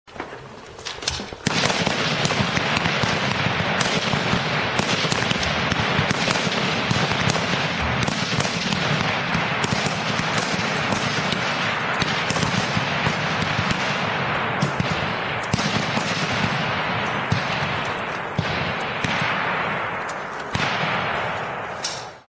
Перестрелка из огнестрельного оружия
Отличного качества, без посторонних шумов.
112_firefight.mp3